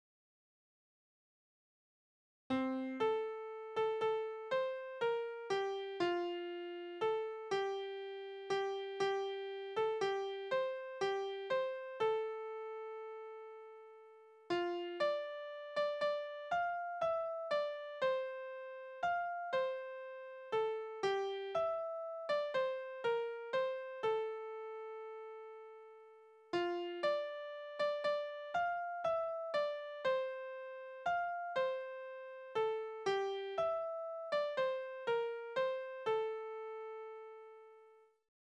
Naturlieder
Tonart: F-Dur
Taktart: 6/4
Tonumfang: Oktave, Quarte
Besetzung: vokal